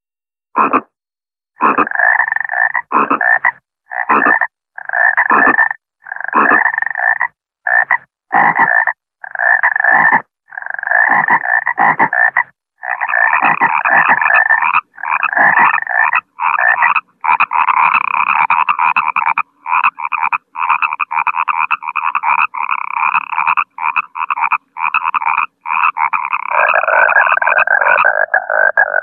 лягушки
жабы
Противно звучит, но прикольно.